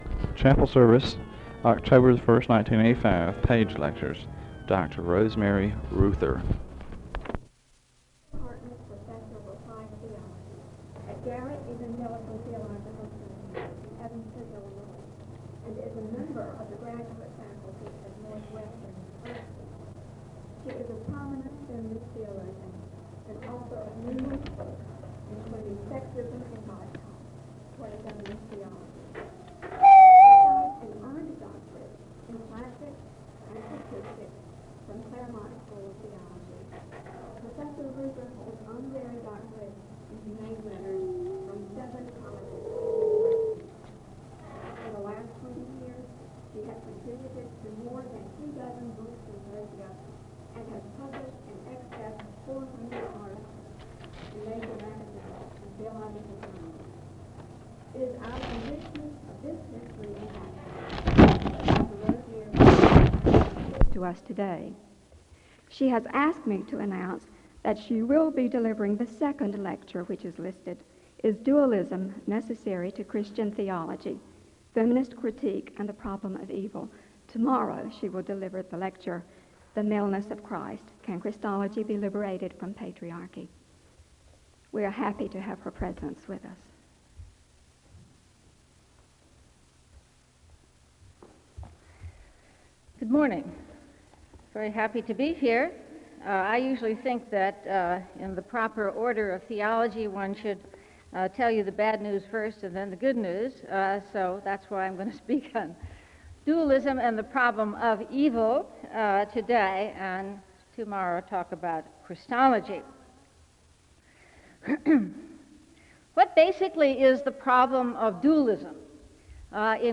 File Set | SEBTS_Page_Lecture_Rosemary_Reuther_1985-10-01.wav | ID: 4a1f6e4b-93e9-43c4-89d9-8d08aaf0c43d | Hyrax